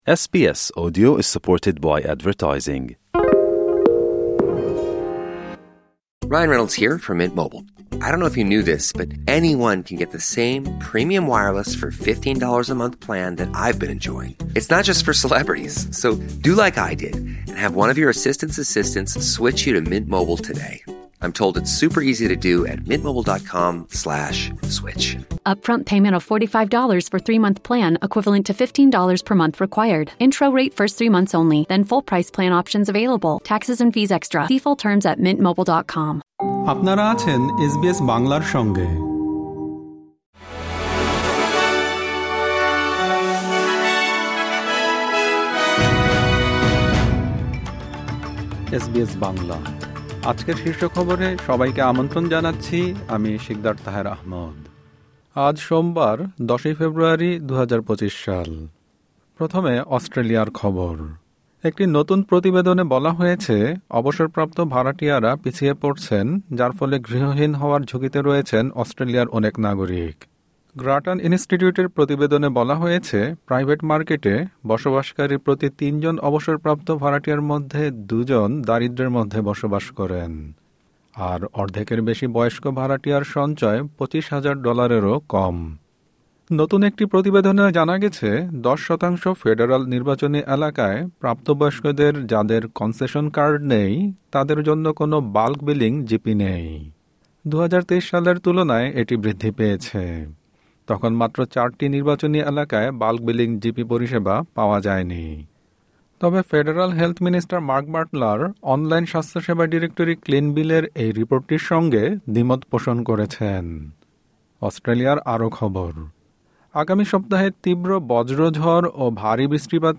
এসবিএস বাংলা শীর্ষ খবর: ১০ ফেব্রুয়ারি, ২০২৫